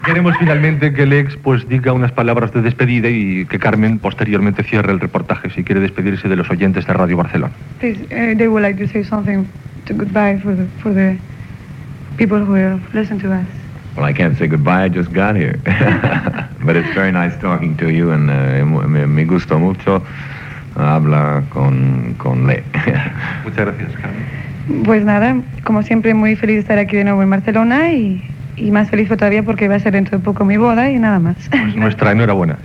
Entrevista a Tita Cervera i Lex Barker.
Extret de Crònica Sentimental de Ràdio Barcelona emesa el dia 29 d'octubre de 1994.